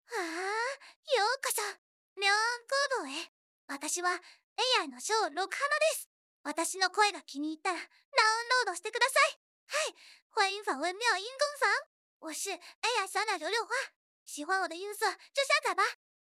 是的，最近制作了很火的一款 动漫人气女主小鸟游六花的GPT-SoVITS模型。